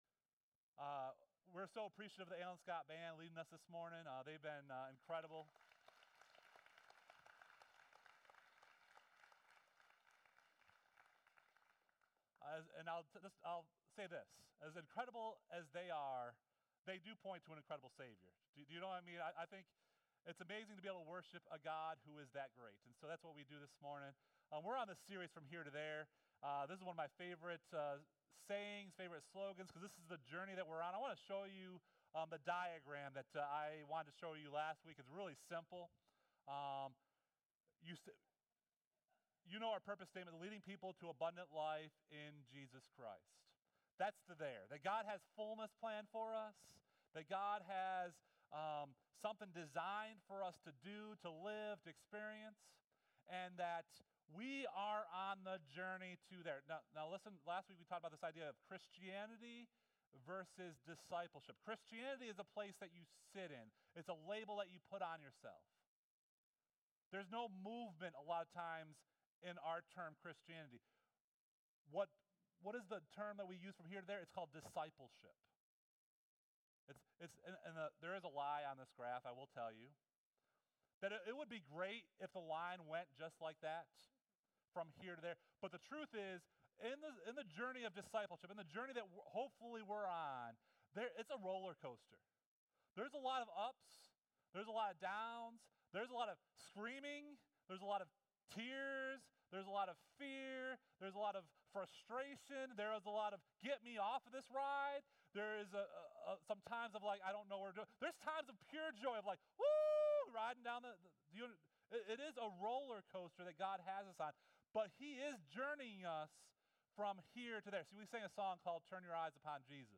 SERMON: 9/17/2017 FROM HERE TO THERE – FOLLOWING AT A DISTANCE (Week 2)